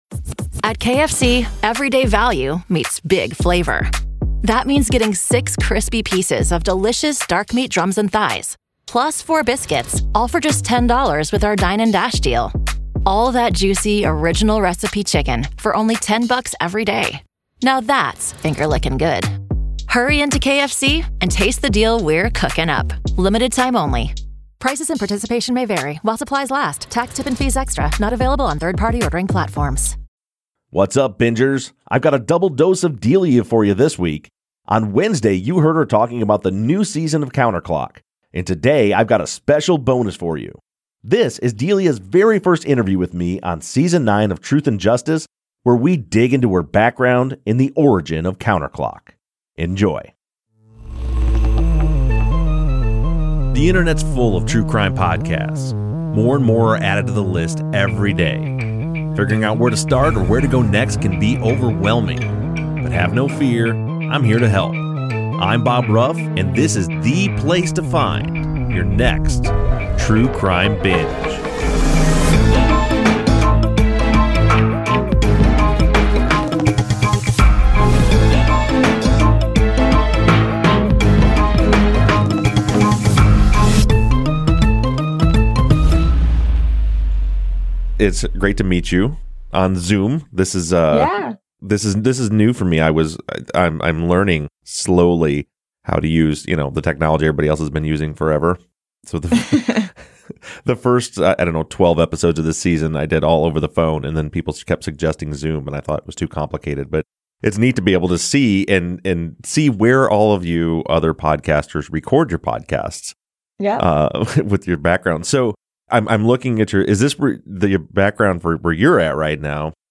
2020 Interview